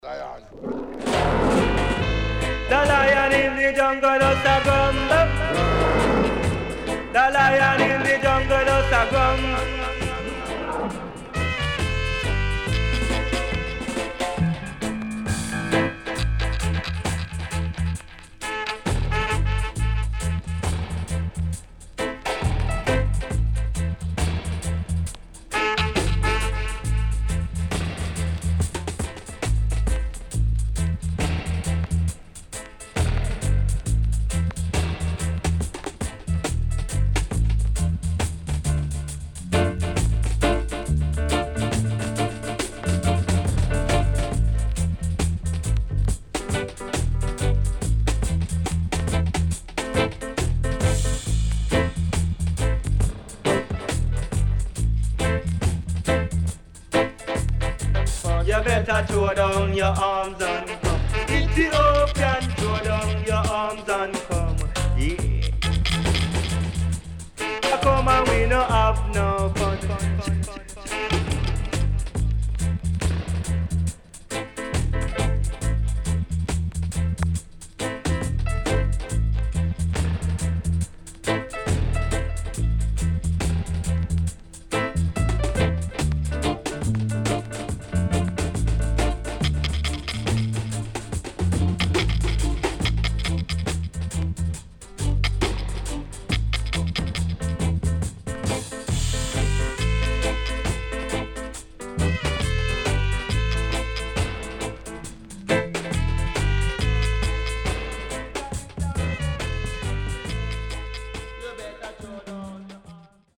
HOME > Back Order [VINTAGE 7inch]  >  KILLER & DEEP
SIDE A:所々チリノイズがあり、少しプチノイズ入ります。